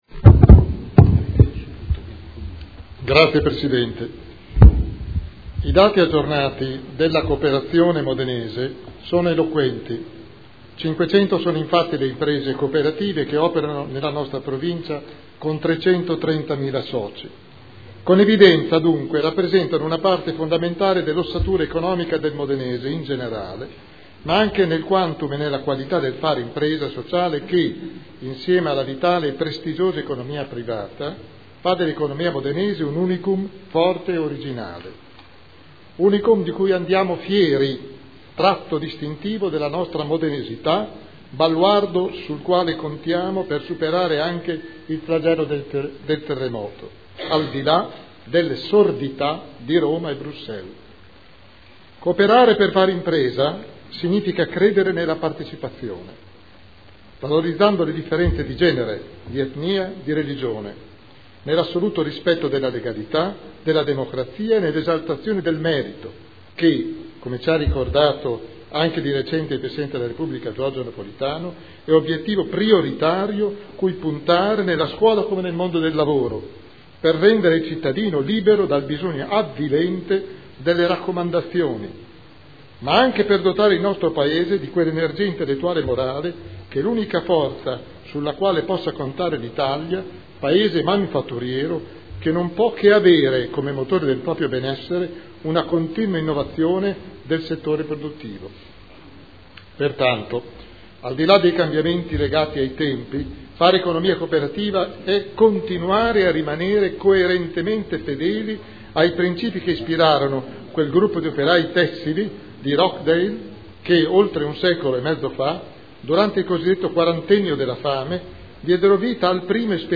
William Garagnani — Sito Audio Consiglio Comunale
Seduta del 22/11/2012. Dibattito su celebrazione dell’Anno internazionale delle cooperative indetto dall’ONU per il 2012